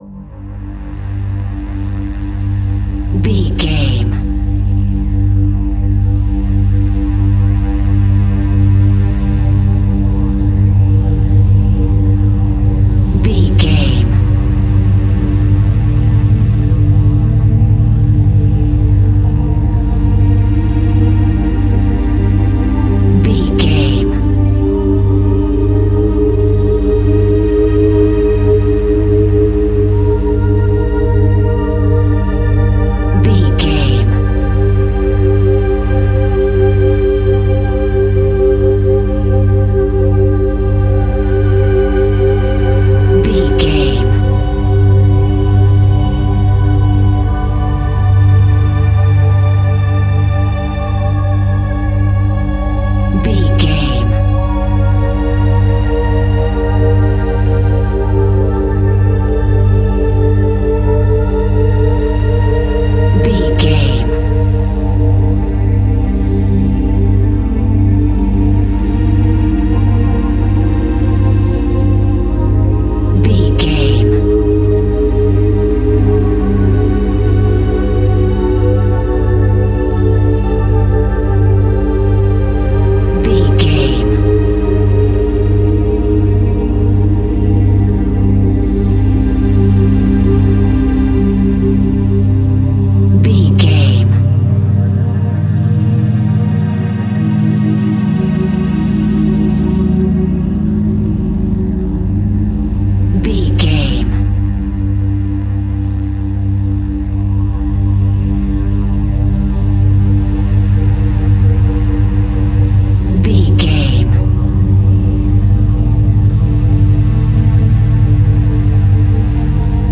Electro Synth Scary Music.
In-crescendo
Aeolian/Minor
tension
ominous
eerie
Horror Synths